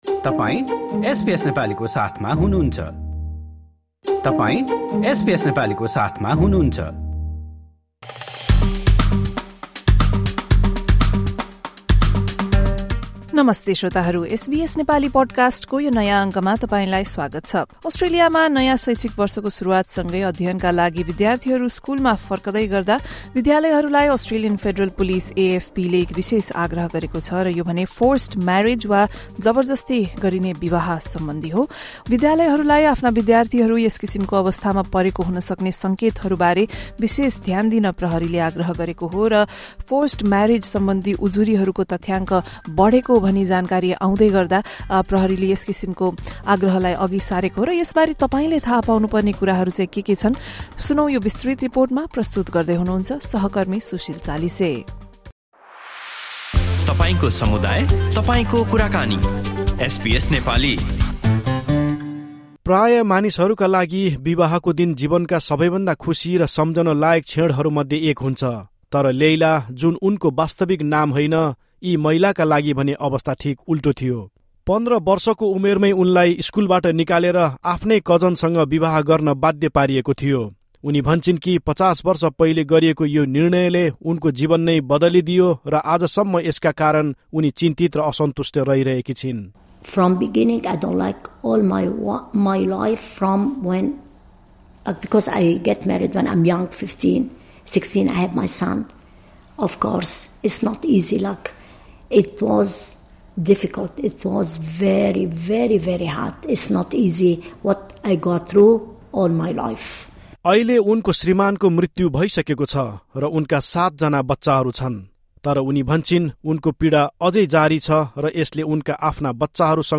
जबरजस्ती विवाहका घटना सम्बन्धि उजुरीहरूको सङ्ख्या बढेको तथ्याङ्कसँगै प्रहरीको यो भनाइ आएको हो। एक रिपोर्ट।